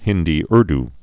(hĭndē-rd, -ûr-)